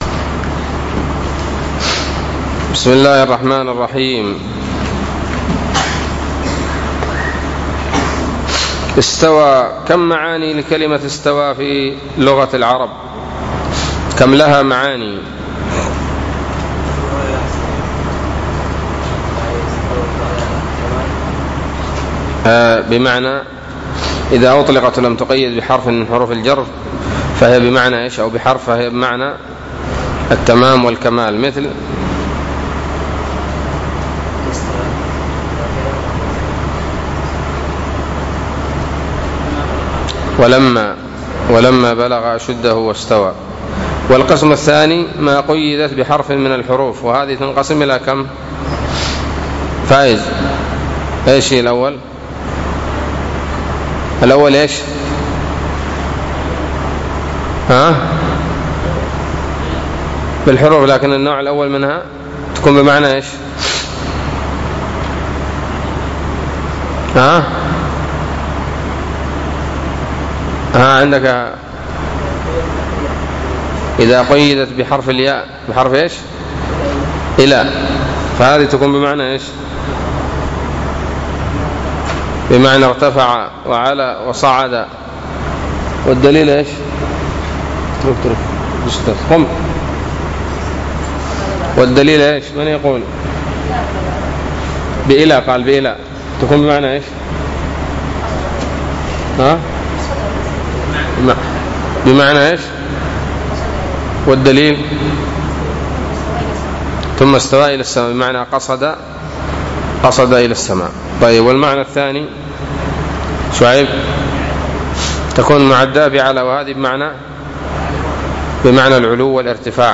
الدرس الثالث والسبعون من شرح العقيدة الواسطية